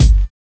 kits/RZA/Kicks/WTC_kYk (63).wav at main